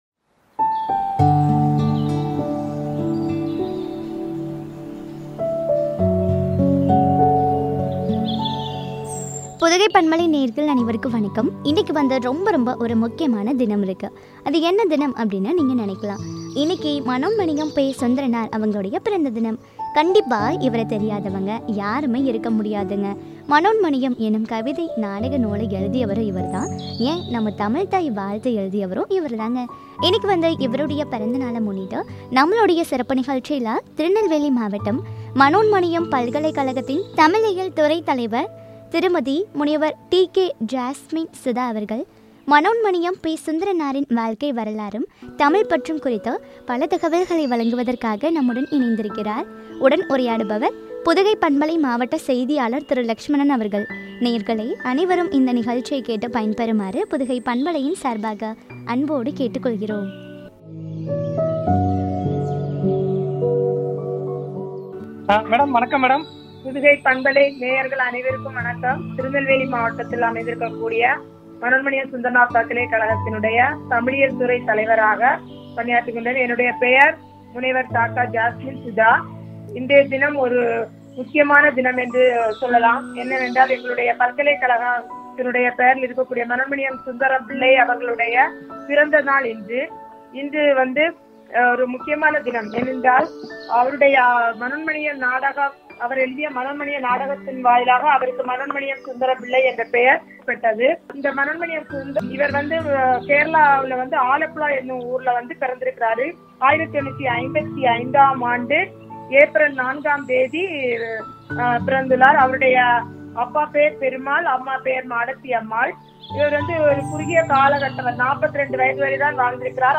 மனோன்மணியம் பெ . சுந்தரனாரின் வாழ்க்கை வரலாறும், தமிழ்ப்பற்றும் பற்றிய உரையாடல்.